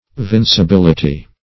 Meaning of vincibility. vincibility synonyms, pronunciation, spelling and more from Free Dictionary.
vincibility.mp3